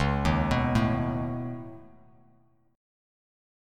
C#7sus2#5 Chord